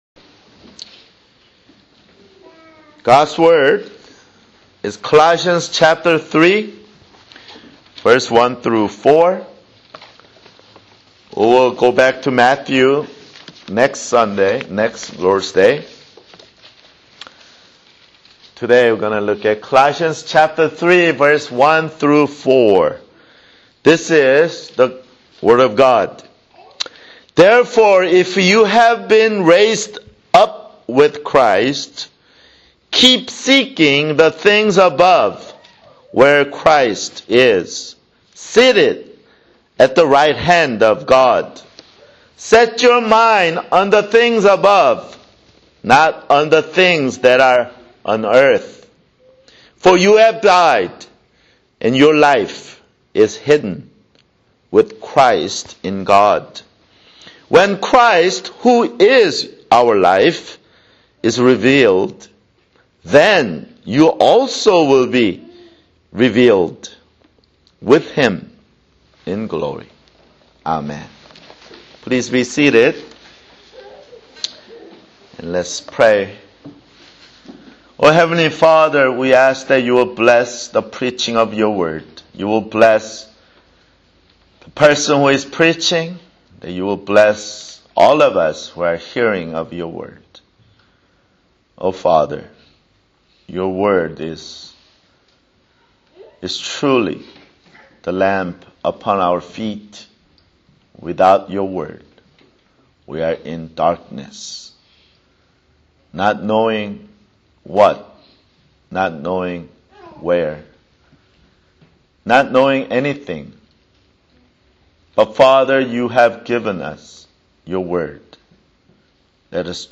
Christ and Heaven OPC: [Sermon] Colossians 3:1-4